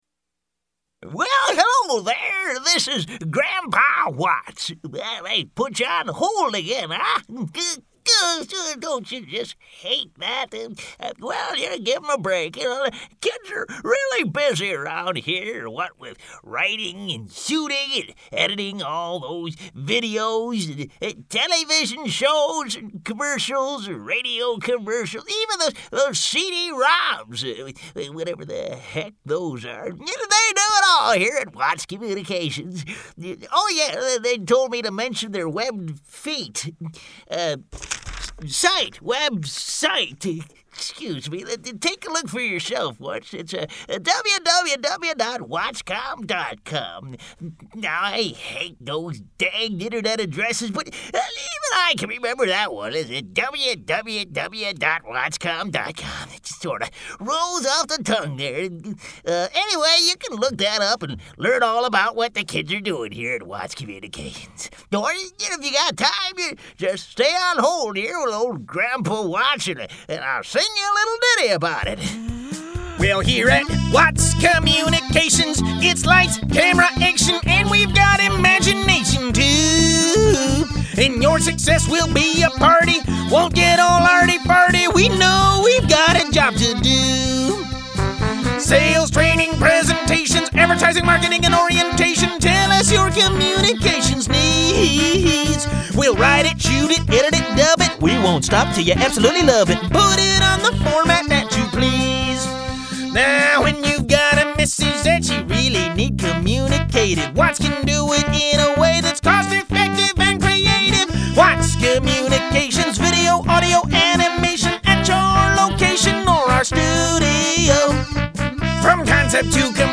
Grandpa Watts On-Hold Messaging